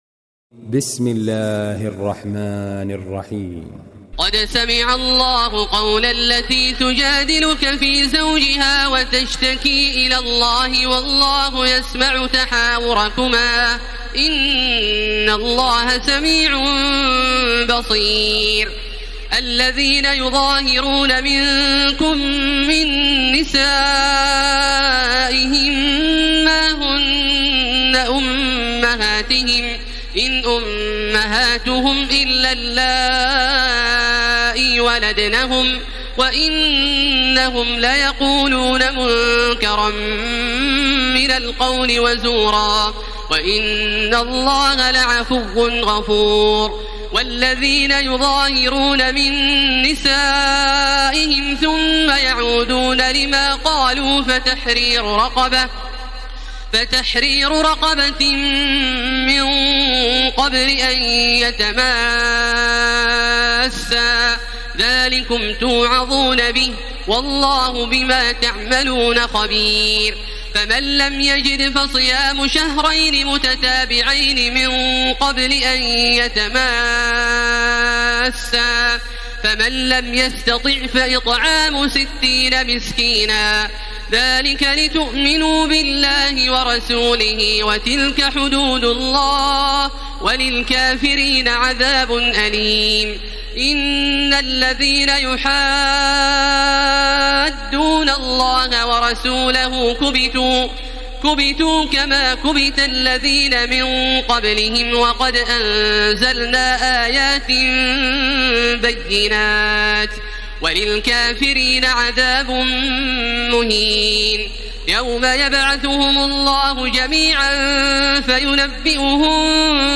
تراويح ليلة 27 رمضان 1434هـ من سورة المجادلة الى الصف Taraweeh 27 st night Ramadan 1434H from Surah Al-Mujaadila to As-Saff > تراويح الحرم المكي عام 1434 🕋 > التراويح - تلاوات الحرمين